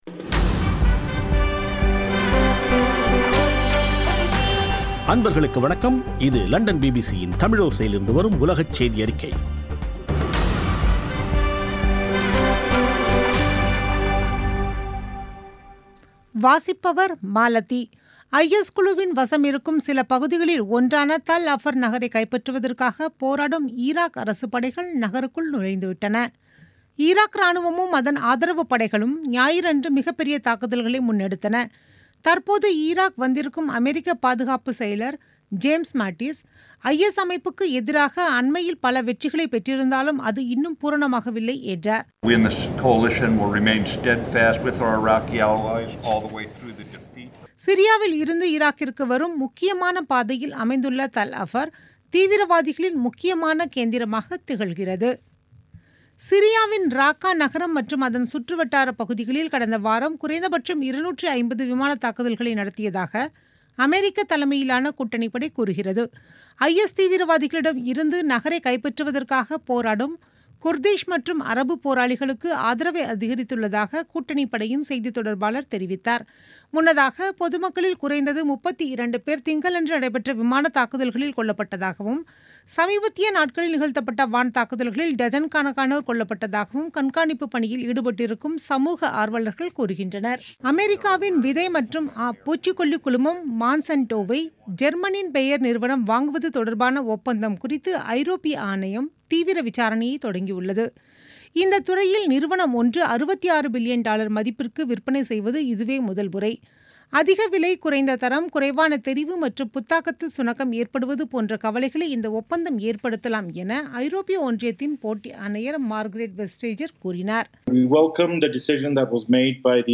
பிபிசி தமிழோசை செய்தியறிக்கை (22/08/2017)